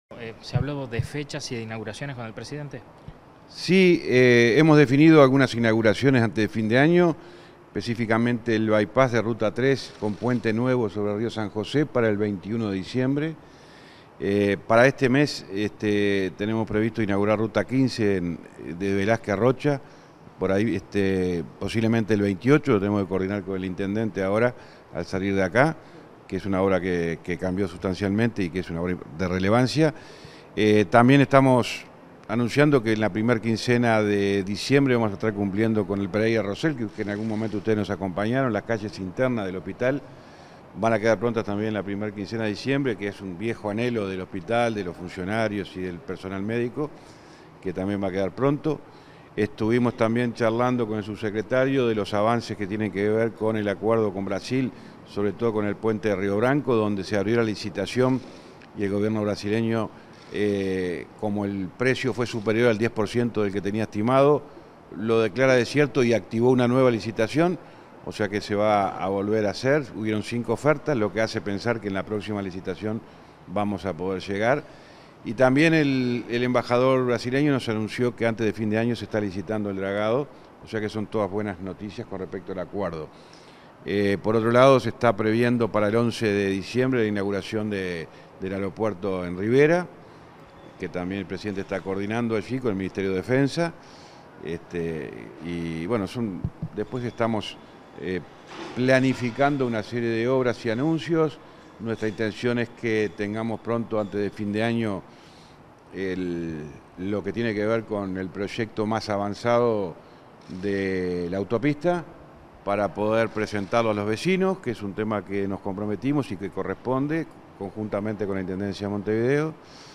Declaraciones del ministro de Transporte y Obras Públicas, José Luis Falero
Declaraciones del ministro de Transporte y Obras Públicas, José Luis Falero 14/11/2023 Compartir Facebook X Copiar enlace WhatsApp LinkedIn Luego de reunirse con el presidente de la República, Luis Lacalle Pou, este 14 de noviembre, el ministro de Transporte y Obras Públicas, José Luis Falero, realizó declaraciones a la prensa.